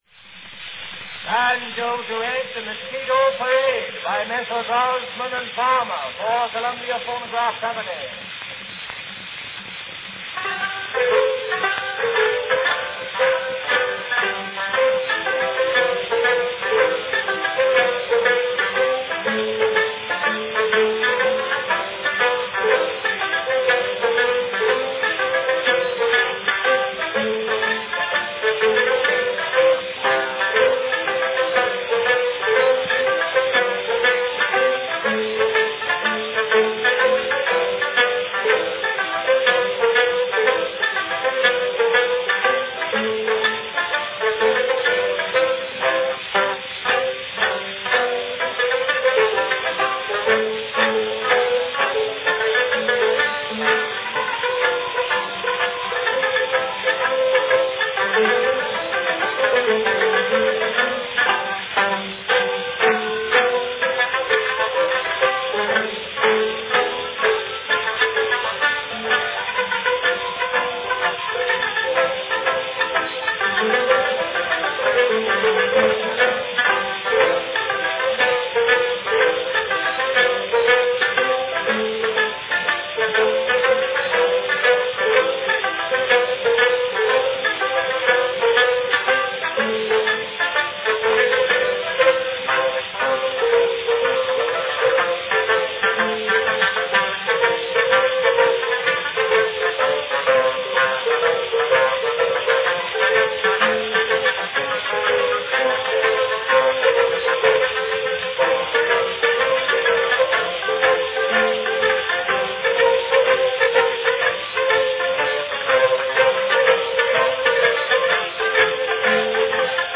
A fun banjo duet from 1901
Cylinder # 31589 (5-inch "grand" cylinder)
Category Banjo duet